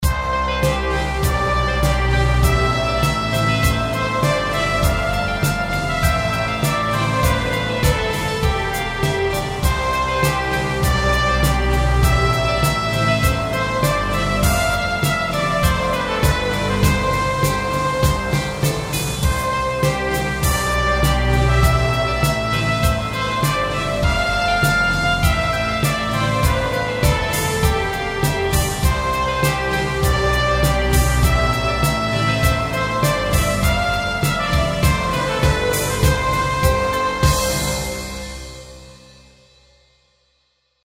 BGM
スローテンポ明るい